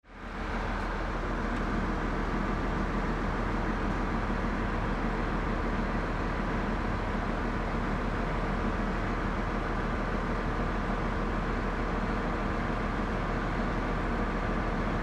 Truck 01
Transportation Sound Effects
truck_01-1-sample.mp3